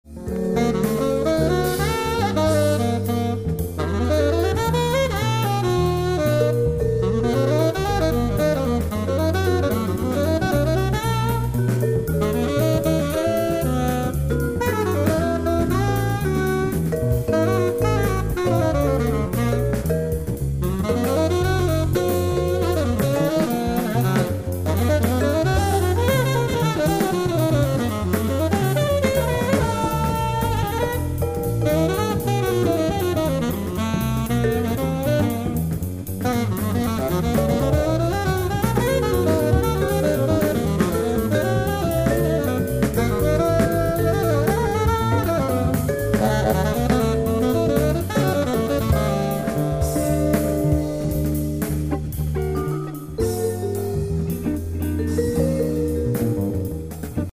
(Impro Tenor sax)